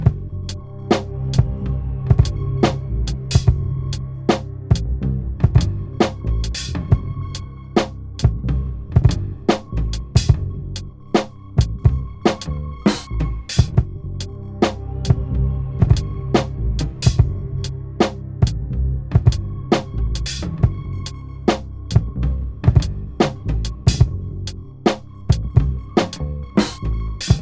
A Boombap lofi vibe sample pack with a jazzy dark ambient aura that make this collection of samples perfect to improvise over and get deep, complex and introspective tracks